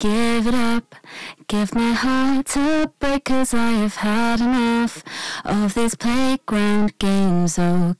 これはその名の通りサチュレーションとなっており、音を少し汚しながら音量を稼ぎ、
違いは歪み具合がこのサチュレーションの場合には緩やかですので、ボーカルなどにも使いやすくなっています。